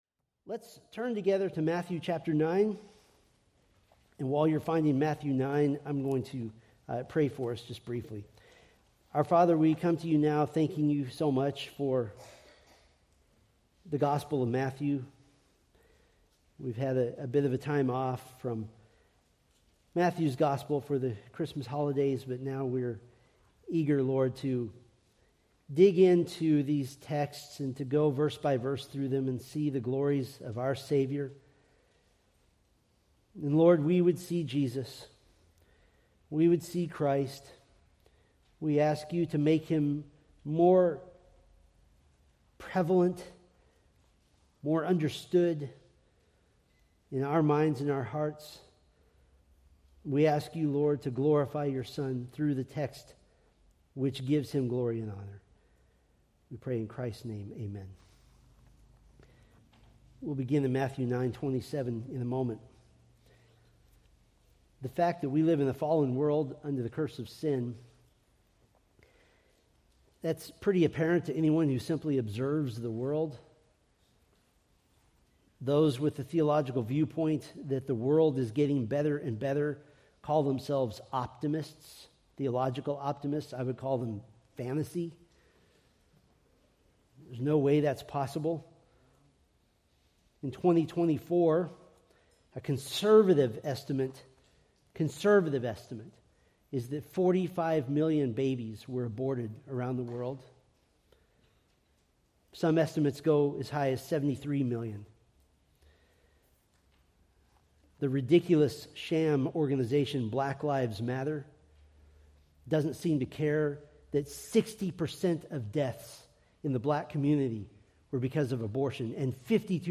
Preached January 12, 2025 from Matthew 9:27-33